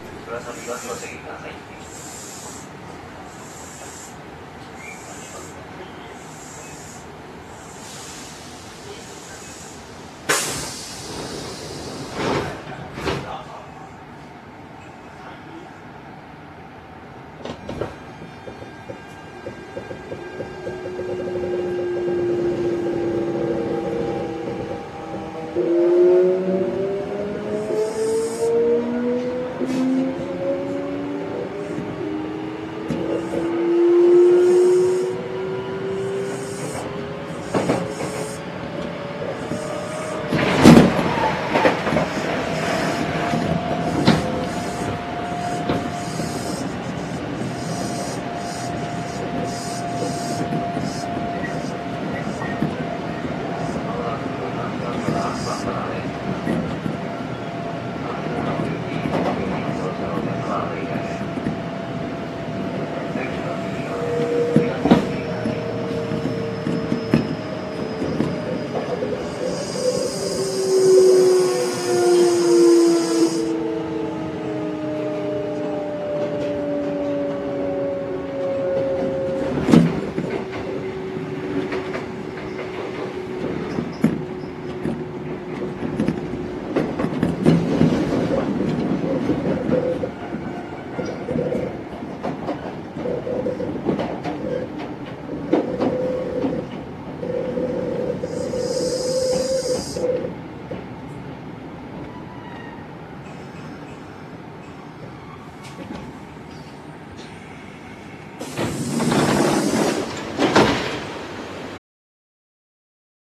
当初は日立ＧＴＯの初期の変調音でしたが、現在はソフト変更されています。
走行音（6402）
収録区間：南大阪線 高見ノ里→河内松原